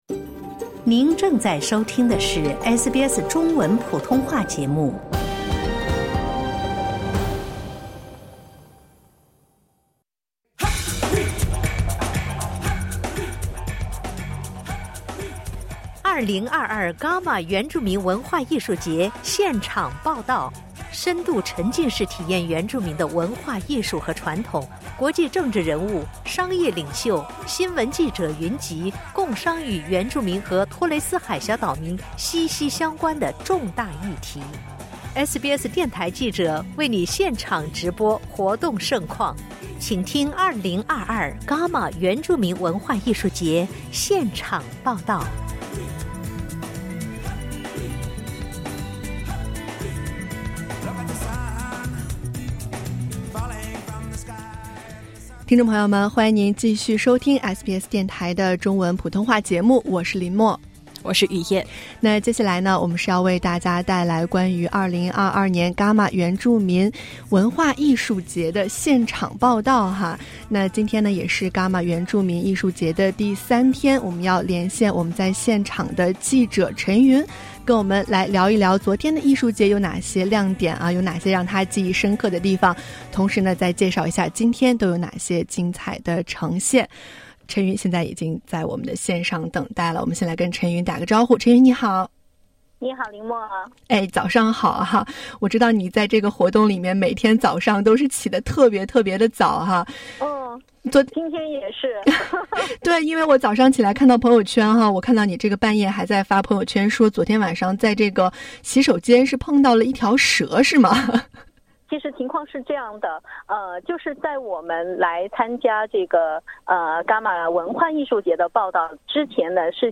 【伽马文化艺术节第三天】特派记者现场报道